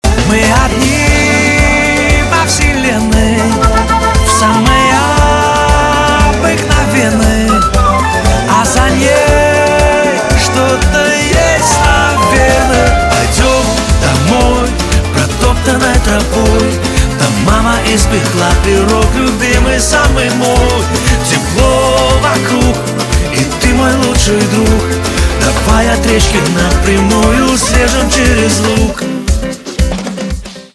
поп
барабаны , гитара , позитивные , веселые